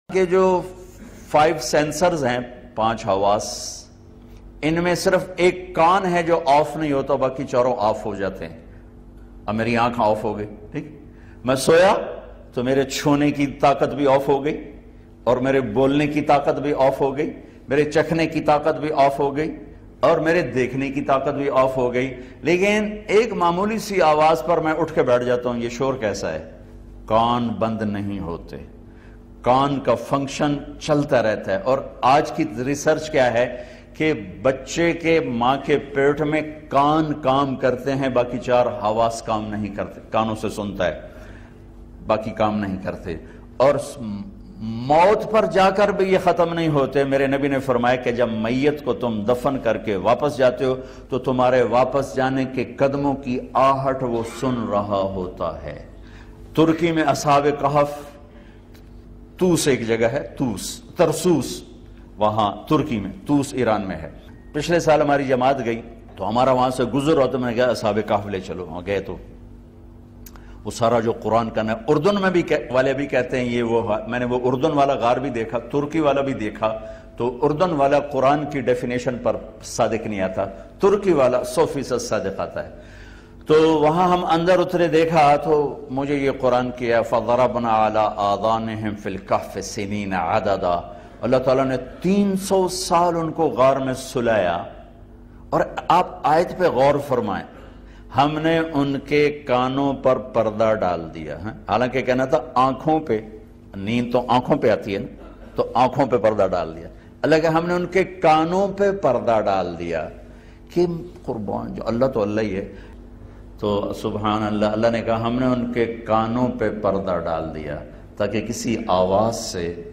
Listen online and download mp3 bayan of Qabar Main Mayat Qadmon Ki Awaz Sunti Hai by Maulana Tariq Jameel.